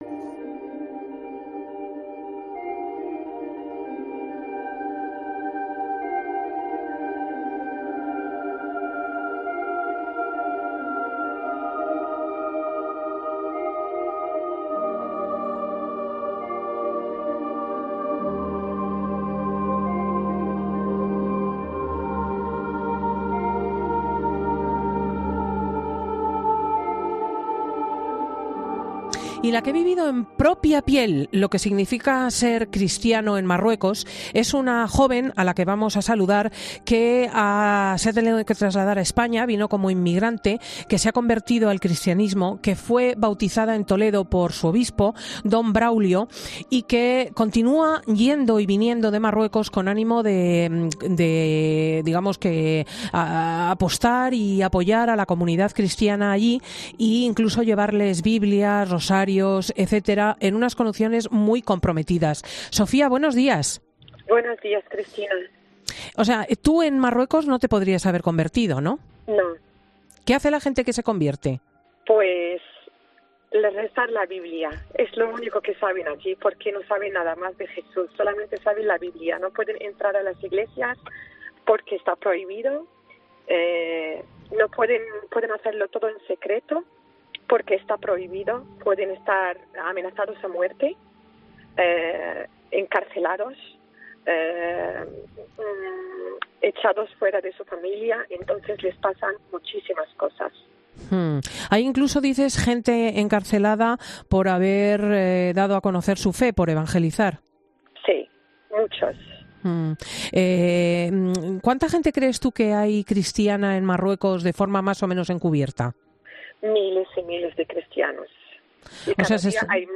El duro testimonio de una marroquí conversa: "En Marruecos, miles de cristianos viven su fe en secreto"